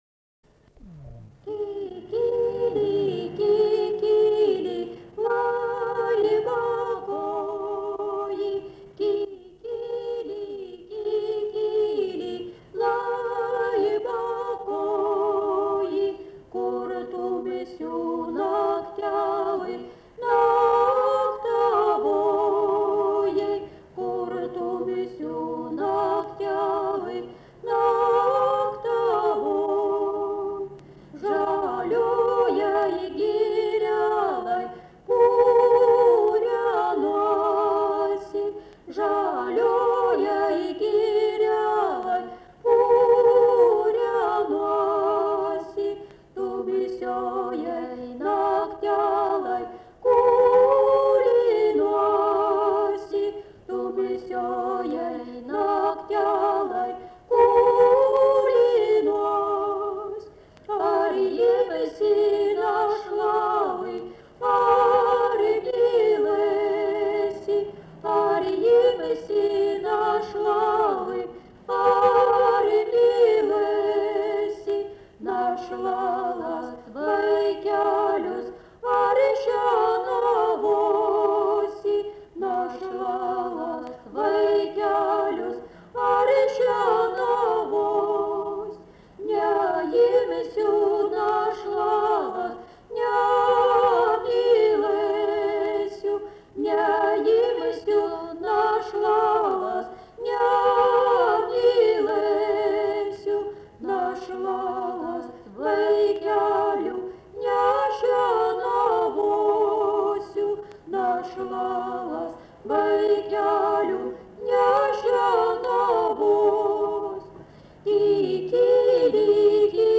Dalykas, tema daina
Erdvinė aprėptis Juodšiliai Vilnius
Atlikimo pubūdis vokalinis